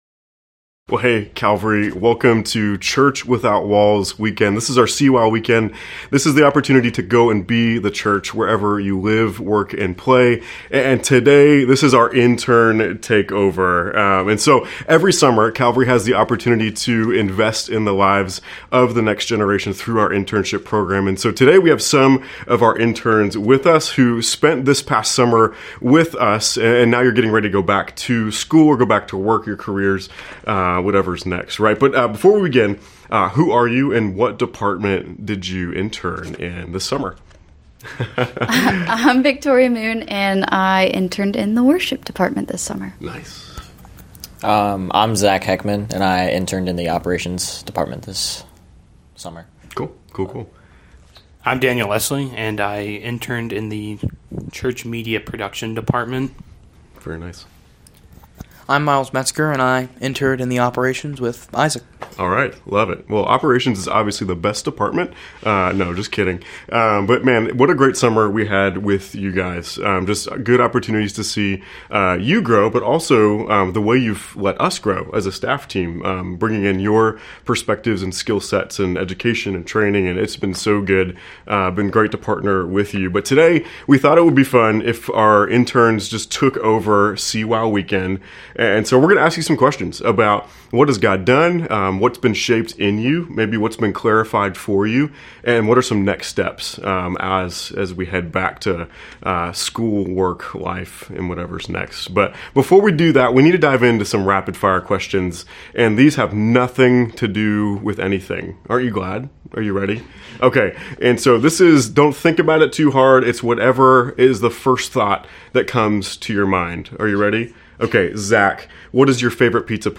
Four of Calvarys summer interns talk about how theyve grown in their faith while working in ministry.